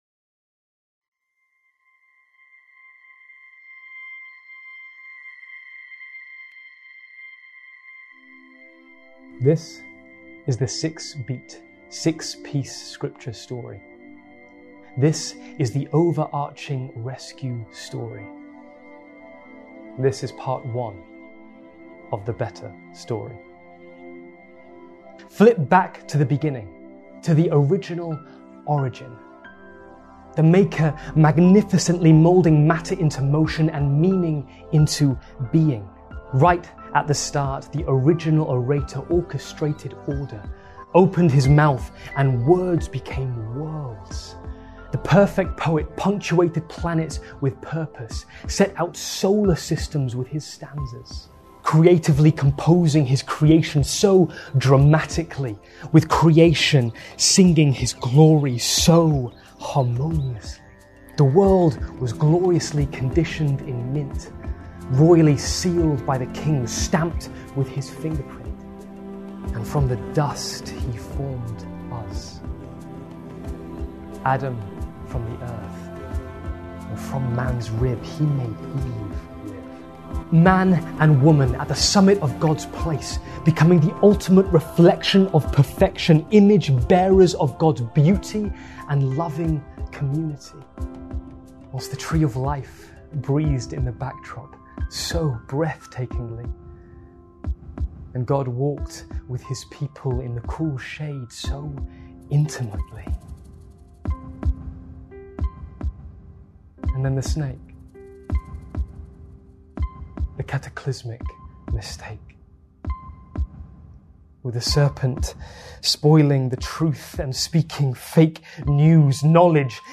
Here's the talk from the 9.30 service and today we're finishing our advent series where we've been looking at our 'Down to Earth' Saviour.